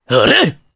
Roi-Vox_Attack2_jp.wav